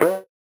Index of /musicradar/8-bit-bonanza-samples/VocoBit Hits